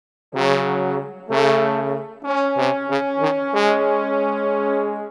Ridin_ Dubs - Horns.wav